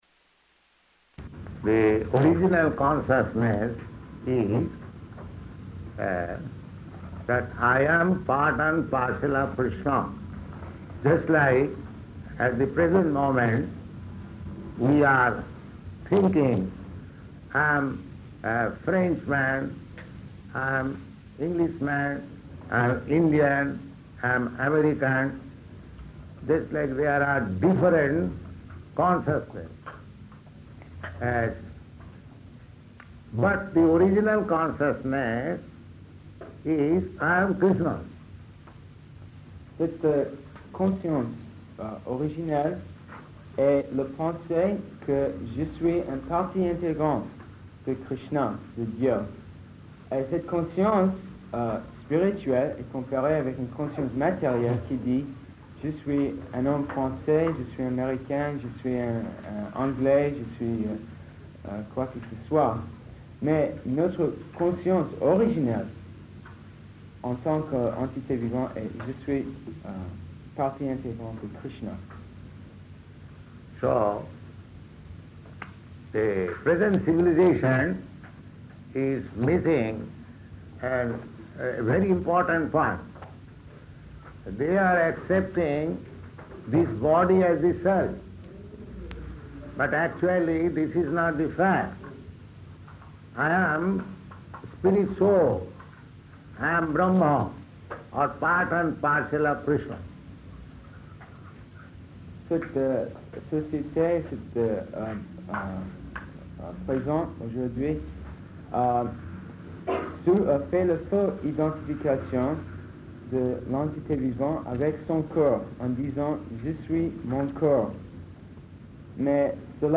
Press Conference [with translator]
Type: Conversation
Location: Paris
[translated into French throughout]